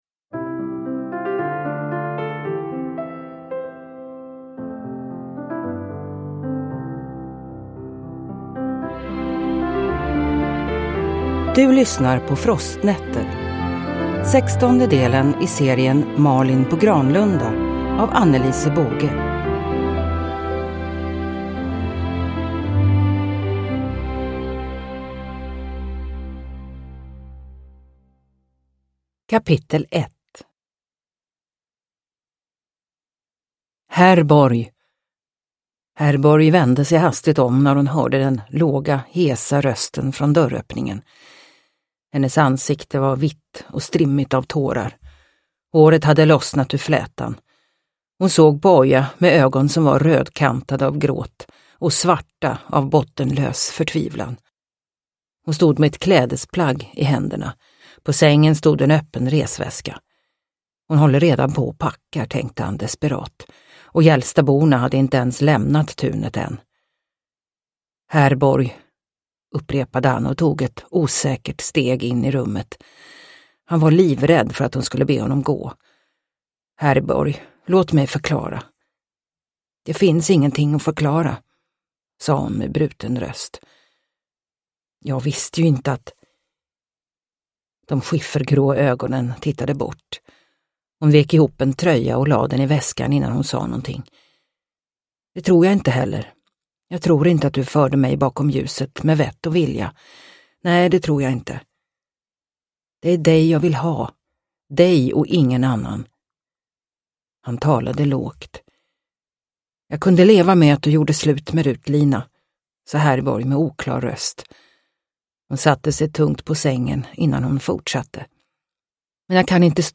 Frostnätter – Ljudbok – Laddas ner